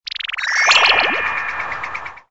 audio: Converted sound effects
SA_mumbo_jumbo.ogg